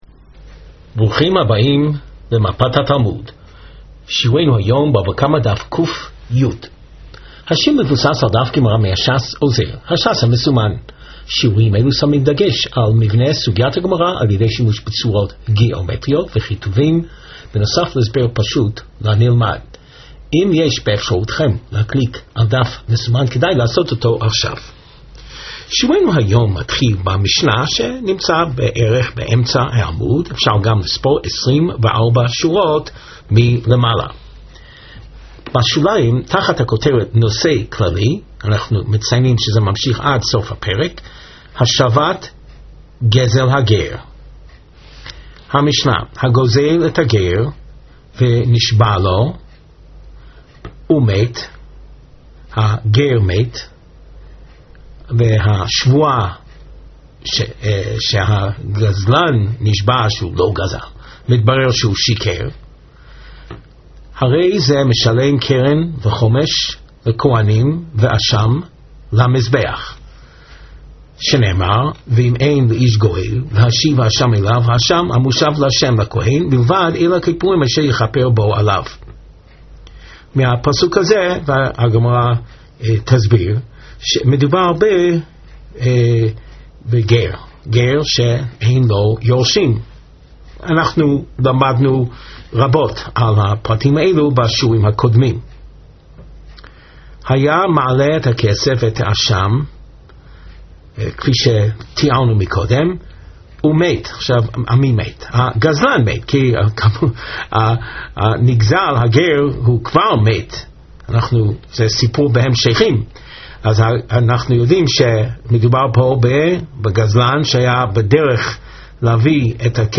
Please click on the above video to hear the Rav give the shiur.